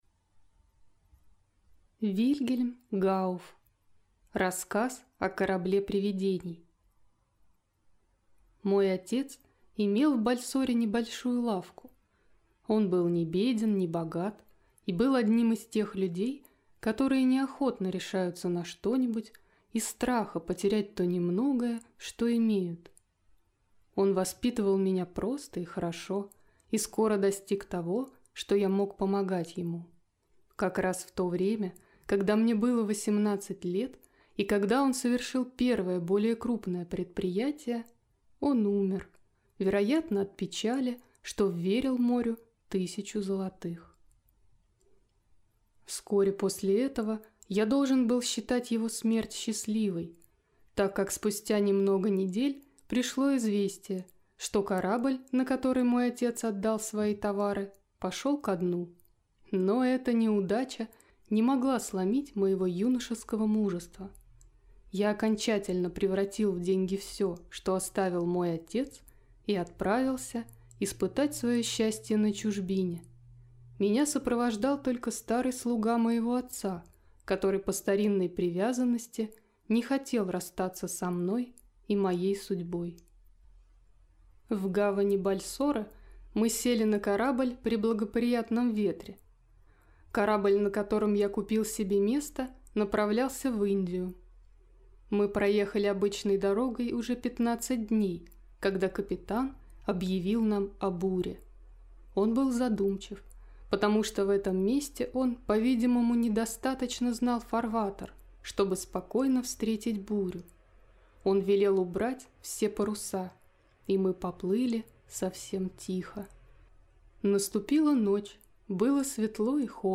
Аудиокнига Рассказ о корабле привидений | Библиотека аудиокниг